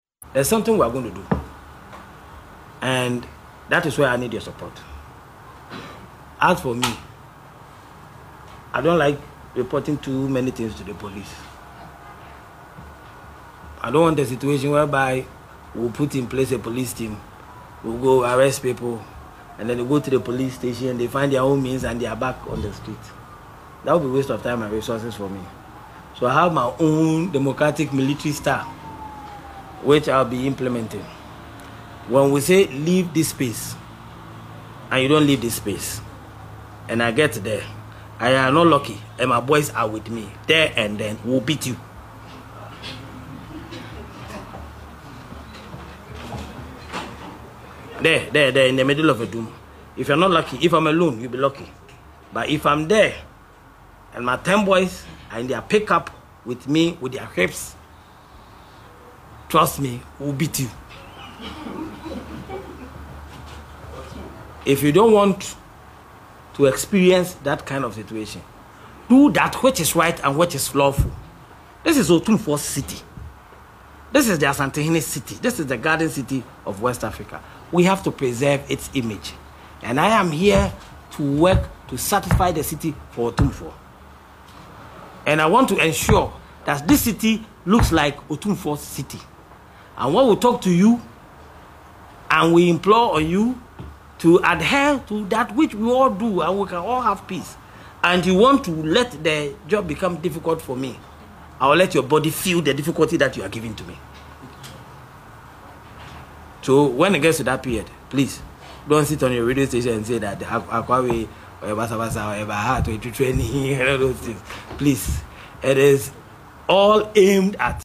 The warning was delivered during a press conference on Monday, April 14, 2025, where he announced plans for a decongestion exercise aimed at clearing traders from pavements and roadsides.